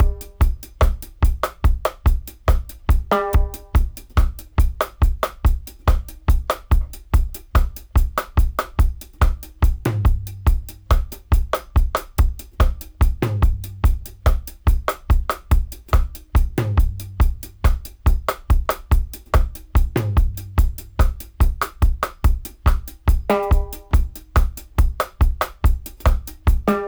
142-DRY-03.wav